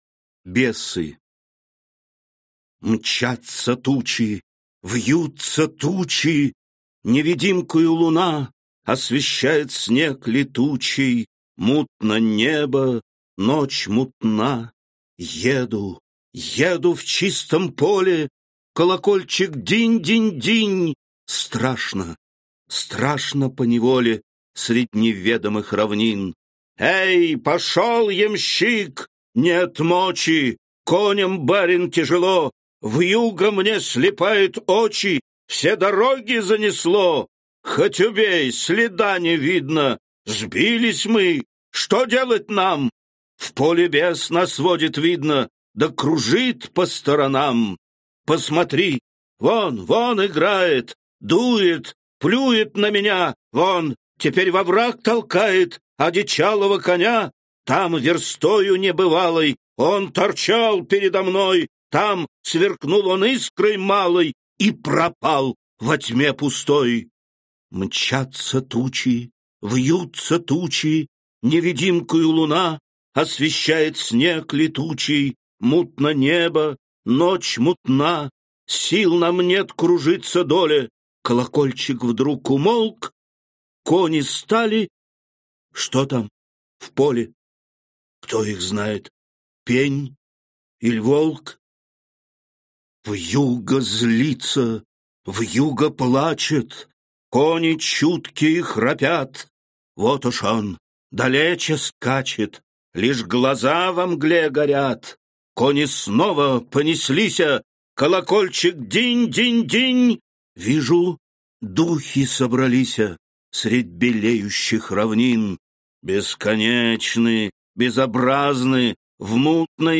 Аудиокнига Хрестоматия по Русской литературе 5-й класс. Часть 1-ая | Библиотека аудиокниг